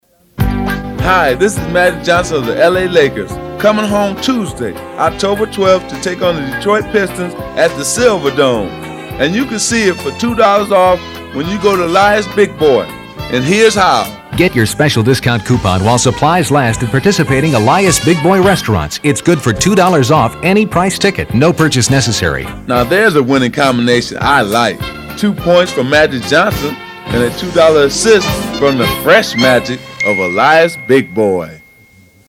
We set up in a private suite high above the court, on which the Pistons were taking pre-season practice.
magic-johnson-radio-mp3.mp3